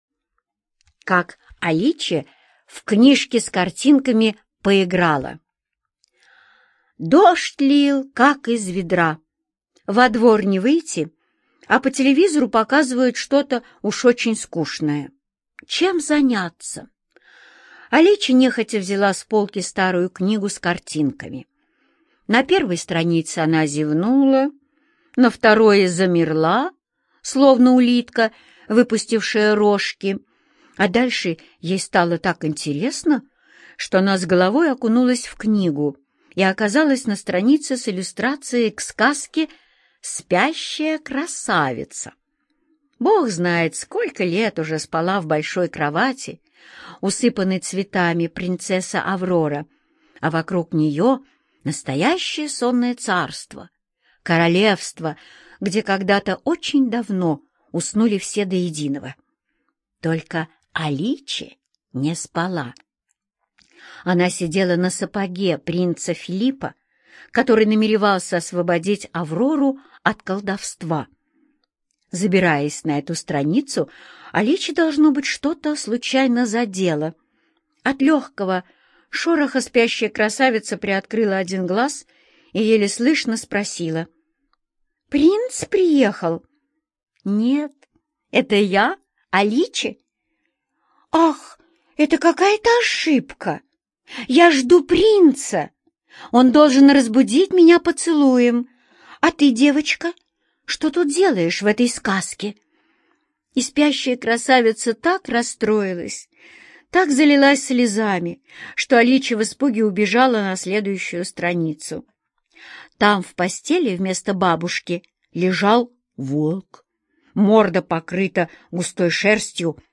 Как Аличе в книжке с картинками поиграла - аудиосказку Родари Д. Однажды Аличе попала в книжку со сказками и пообщалась с их героями.